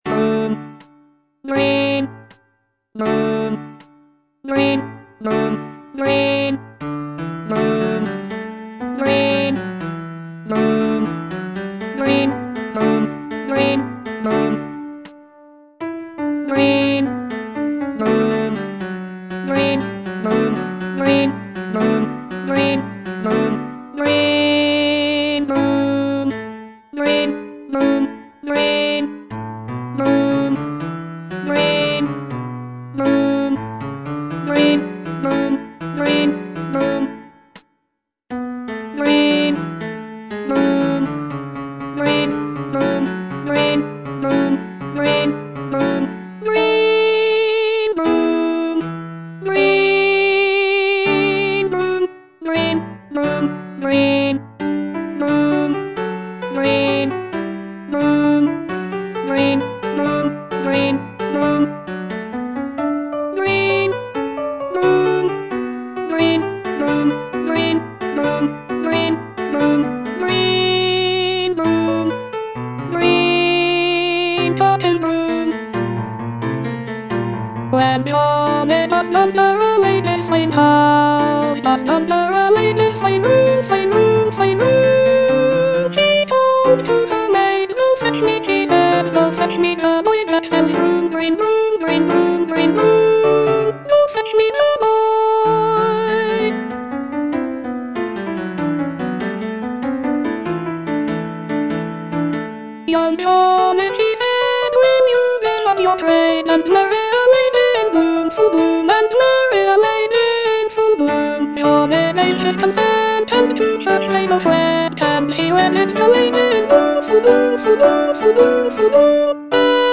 Alto II (Voice with metronome)